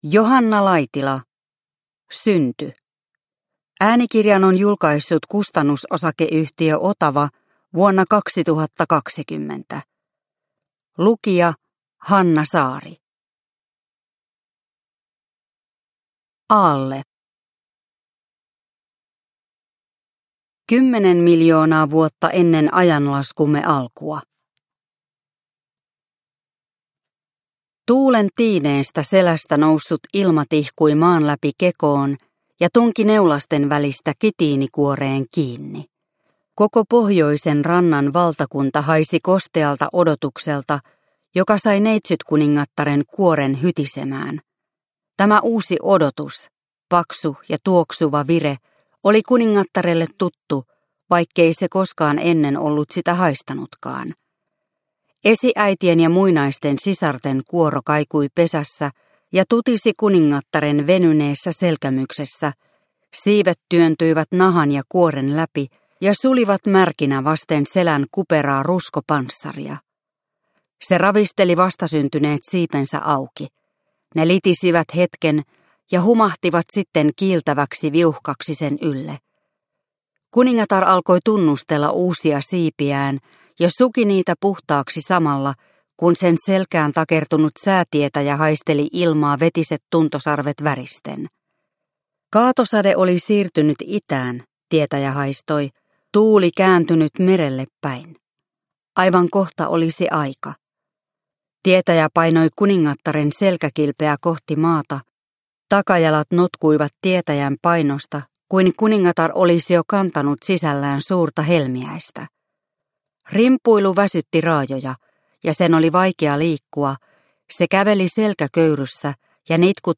Synty – Ljudbok – Laddas ner